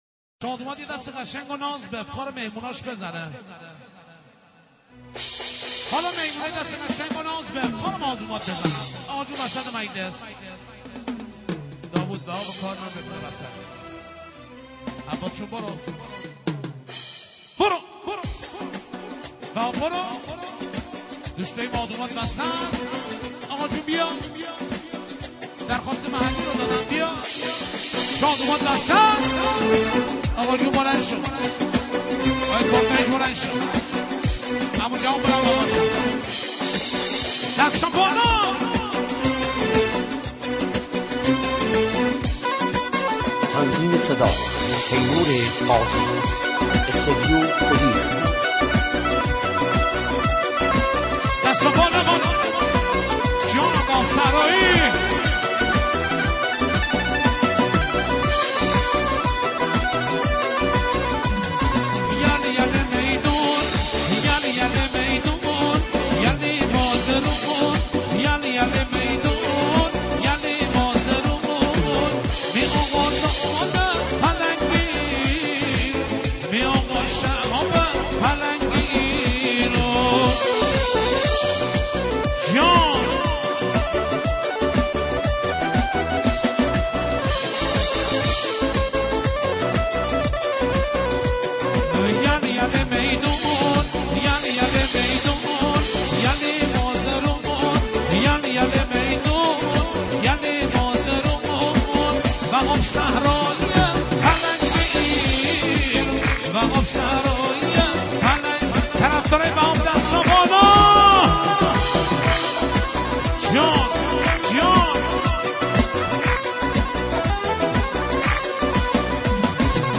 ترانه محلی و زیبای
Beautiful And Happy Song